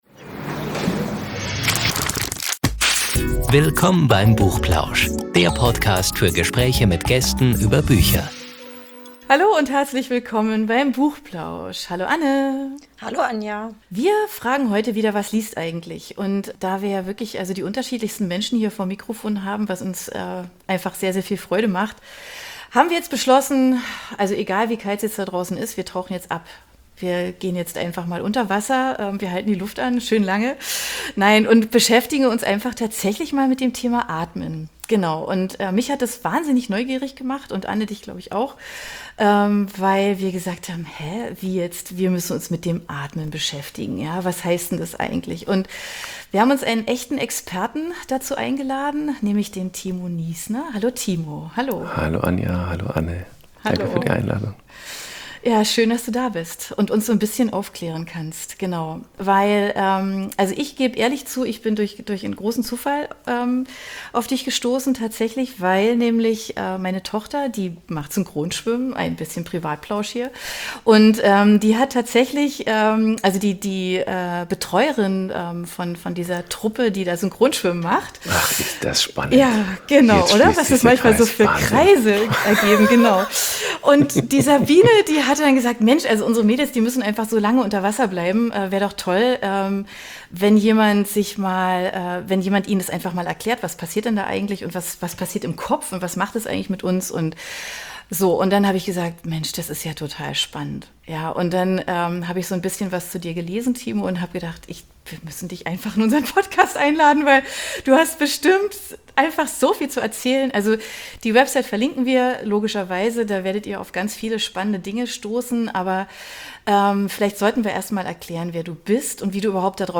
Ein Gespräch über Barrierefreiheit im Alltag, den Hörfilmpreis und großartige Hörbücher